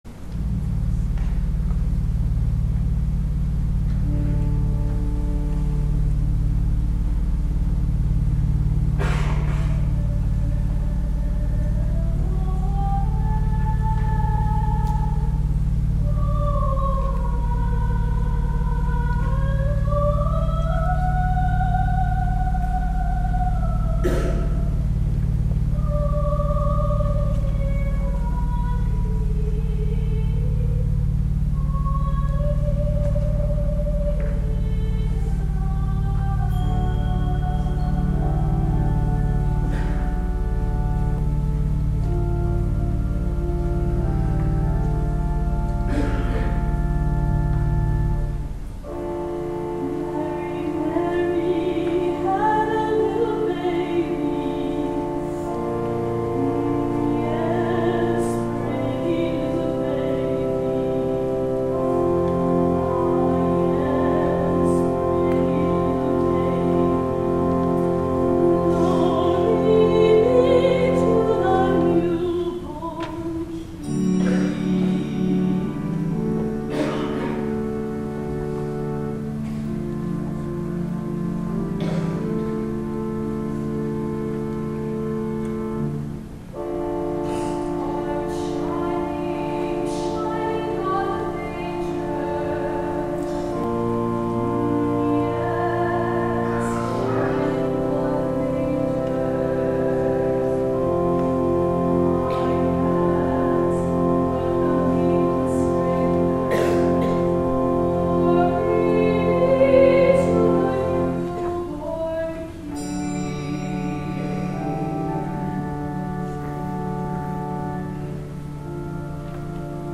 CANDLELIGHT SERVICE
INTROIT